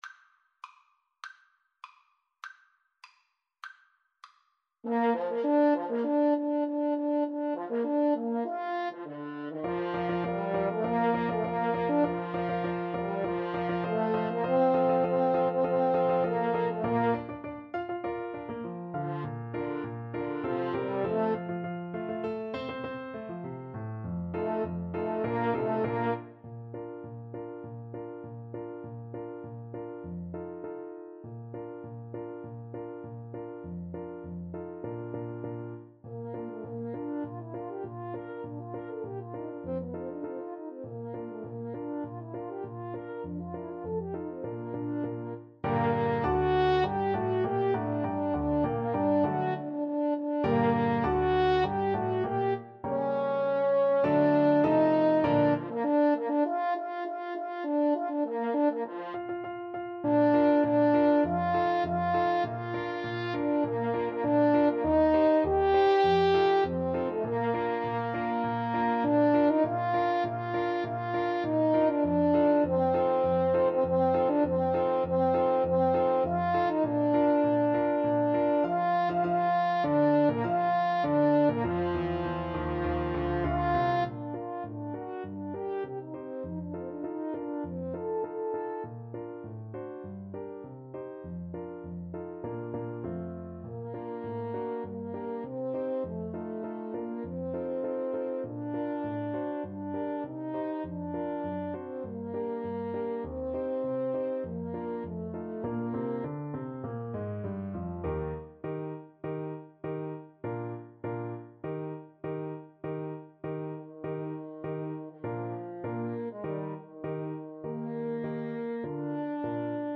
2/4 (View more 2/4 Music)
Bb major (Sounding Pitch) (View more Bb major Music for Trumpet-French Horn Duet )
Classical (View more Classical Trumpet-French Horn Duet Music)